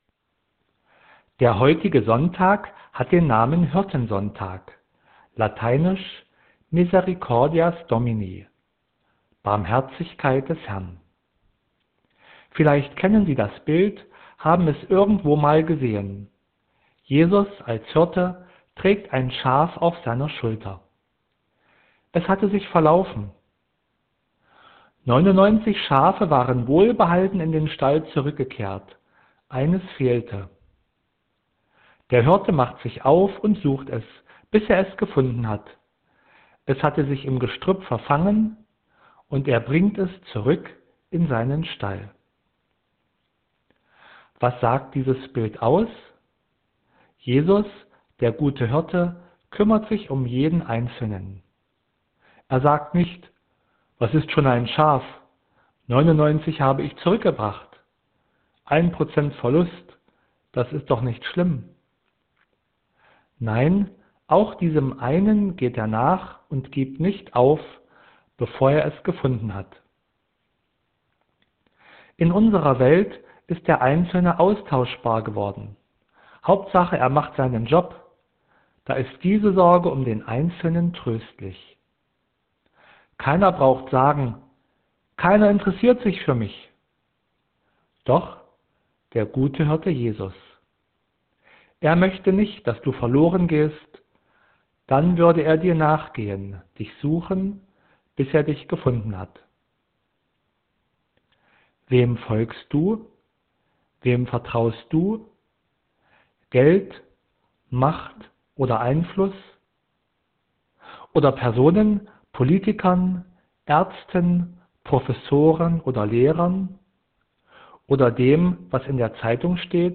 Täglich veröffentlicht die Landeskirche Anhalts als Video oder Audio ein geistliches Musikstück mit Musikerinnen und Musikern aus Anhalt sowie Informationen dazu und der jeweiligen Tageslosung mit Lehrtext.